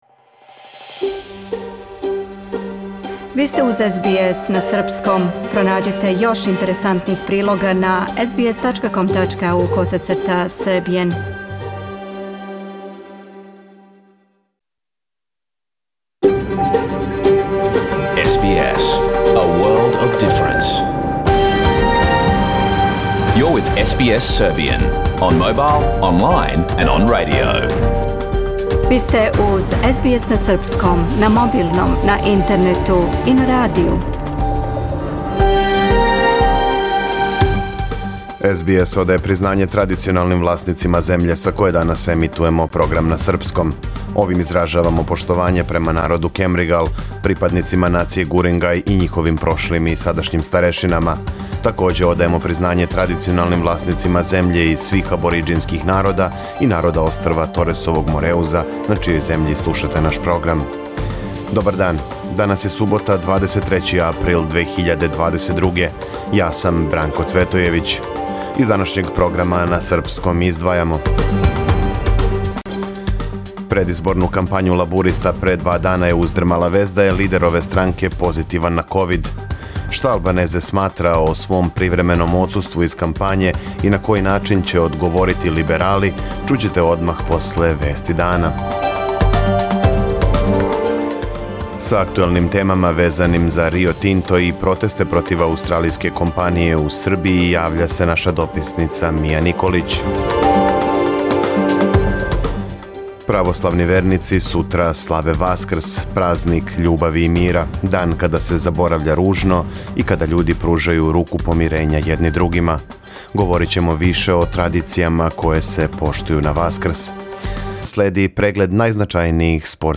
Програм емитован уживо 23. априла 2022. године
Ако сте пропустили нашу емисију, сада можете да је слушате у целини као подкаст, без реклама.